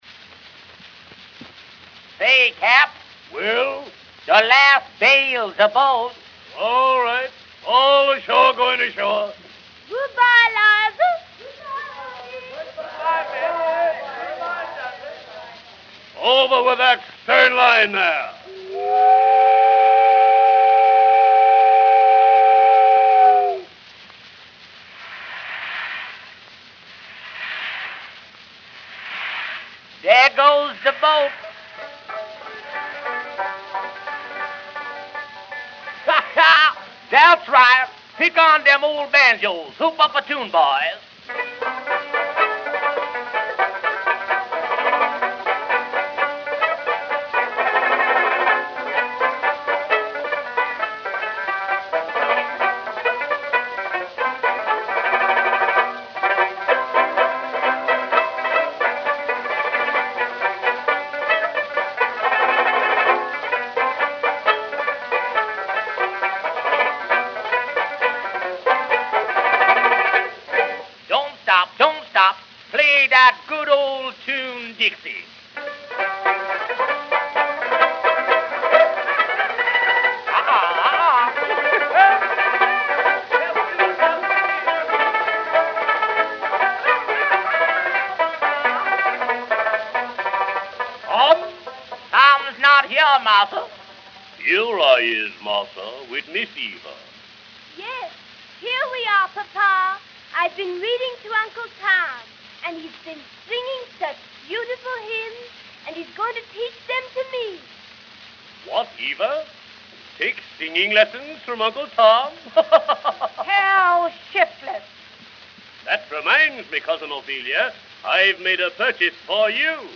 Depite the scratchiness of this recording, it's a very eloquent means of access to the way Uncle Tom's Cabin was heard by at least a generation of American audiences.
It was recorded in November, 1910, on one of Edison's "Four Minute" Ambersol Cylinders.
Spencer almost certainly enacts all the male voices, and it's possible that a single woman does Ophelia, Eva and Topsy. Within its four minute limits the program contains a mix of travelogue (the sounds of a steamboat) and musical variety show (with clear minstrel show origins) along with a gesture toward the sentimental and religious elements in Stowe's story (Eva and Tom).
One of the things you can hear very clearly is the essentializing or stereotyping of the book's characters: Topsy's laugh or Ophelia's "How shiftless!" stand for, respectively, the African American pickaninny and the New England spinster.